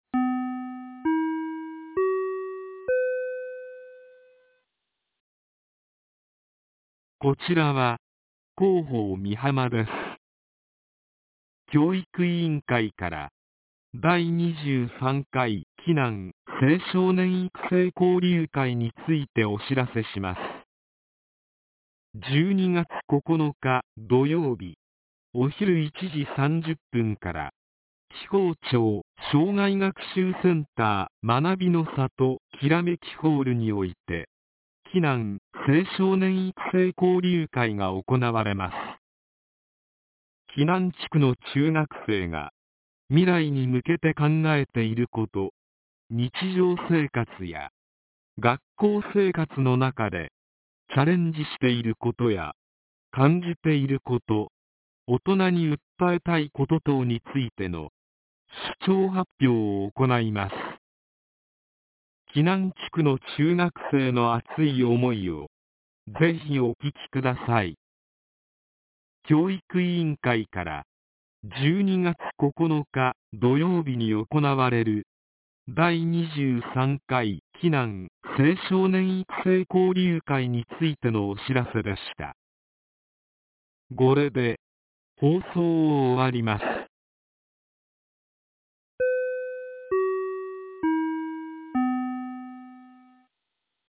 ■防災行政無線情報■
放送内容は下記の通りです。